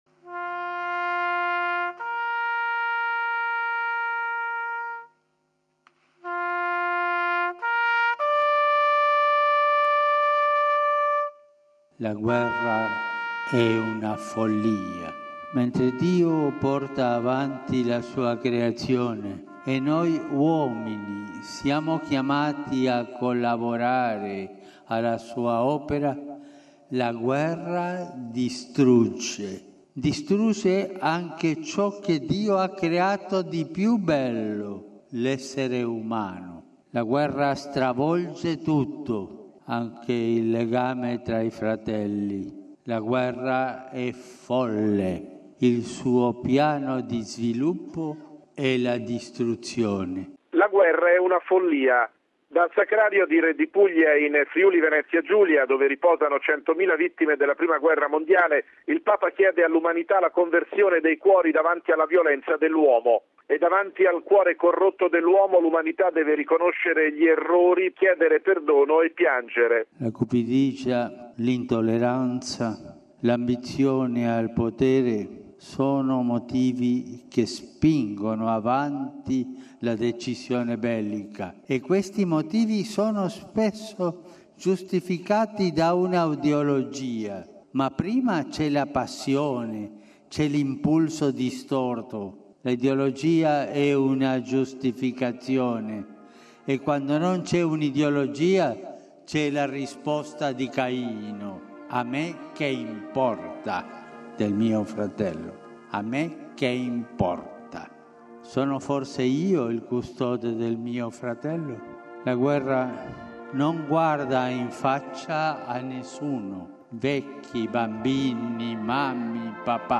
“La guerra è follia”: dal Sacrario di Redipuglia, in provincia di Gorizia, nel centenario dell’inizio della Prima Guerra Mondiale, il Papa chiede all’umanità la conversione del cuore, perché oggi si sta combattendo una terza grande guerra, “con crimini, massacri, distruzioni”. Nella Messa celebrata sotto la pioggia davanti ad almeno 15 mila persone, ha pregato per i caduti di tutte le guerre. 320 concelebranti, 60 vescovi, di cui 13 ordinari militari.